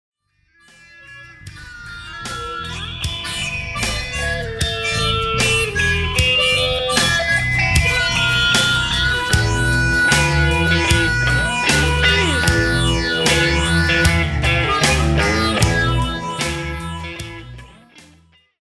This snippet of the track is really just the play out on the 2nd version (much heavier than the original accoustic blues stomp), the harmonica played through a Electro Harmonix Polyphase is definately my favourite thing about this version.